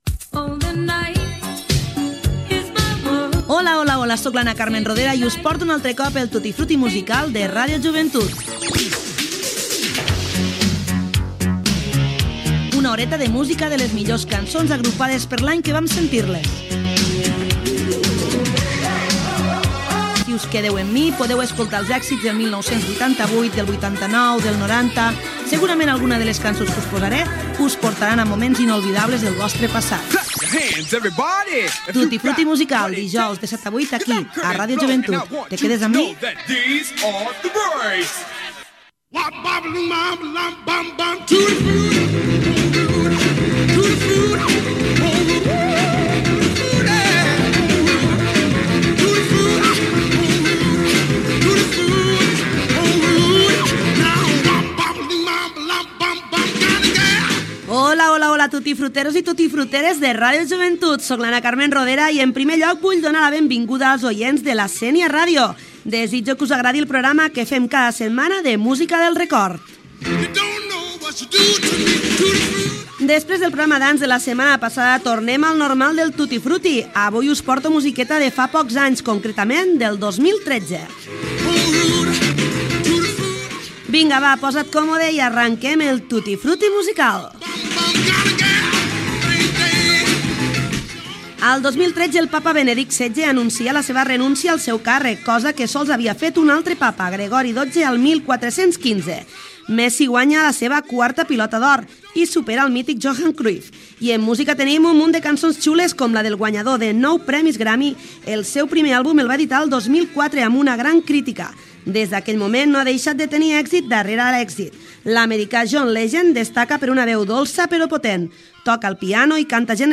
Careta, presentació i inici del programa dedicat a la música que sonava el 2013.
Musical